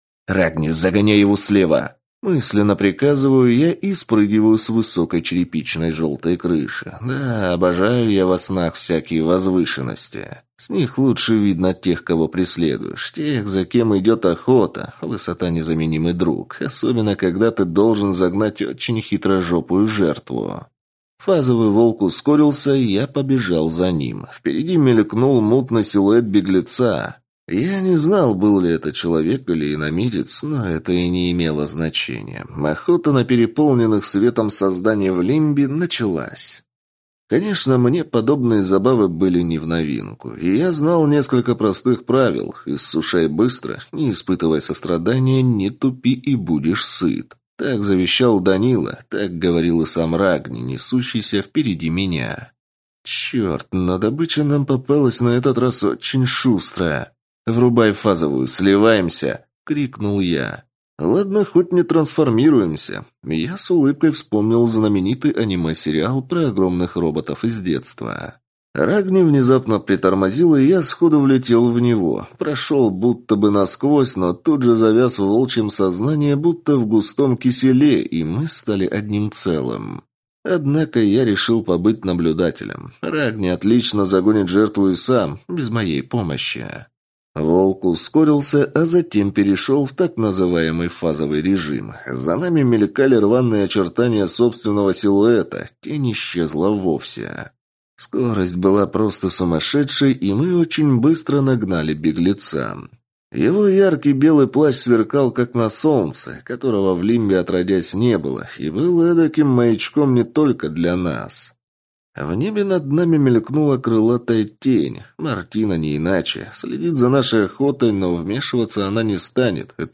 Аудиокнига Час пробуждения | Библиотека аудиокниг